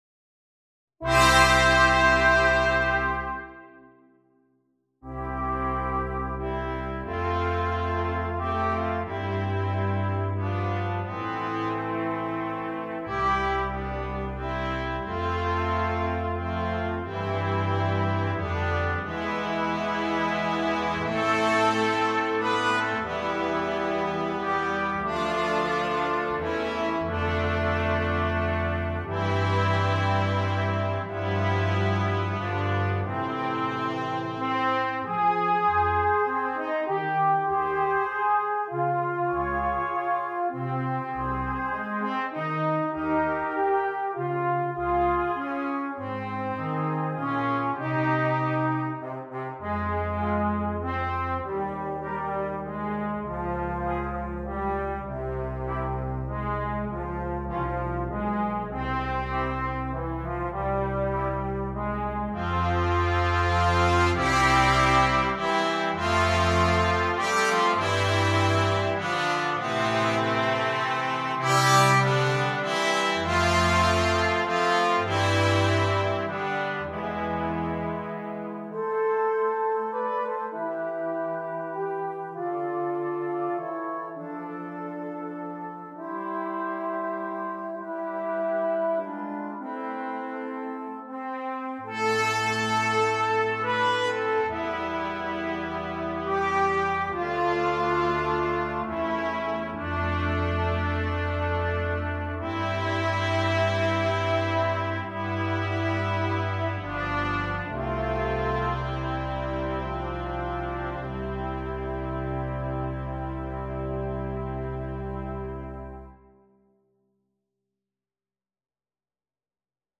торжественную мелодию
инструментальный